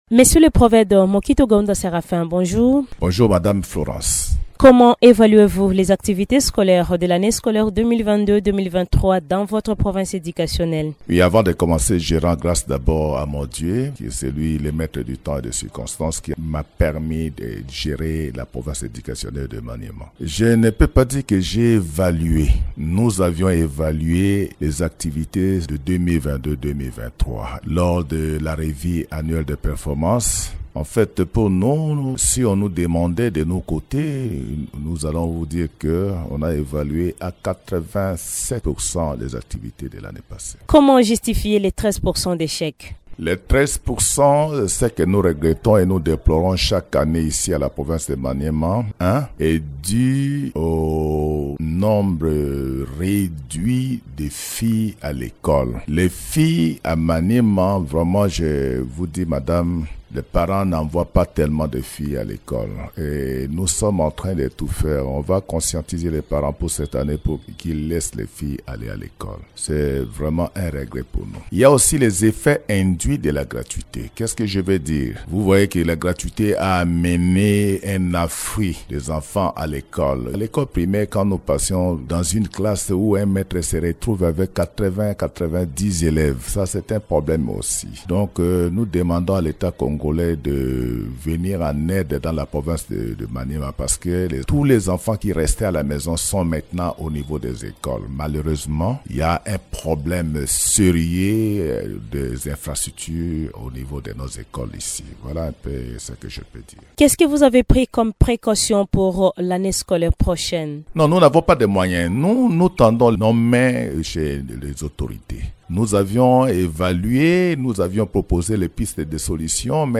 Invité de Radio Okapi, il lance une mise en garde contre les chefs d’établissements scolaires du Maniema 1 réfractaires à cette mesure du ministre de l’EPST.